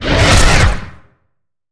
Sfx_creature_squidshark_chase_os_05.ogg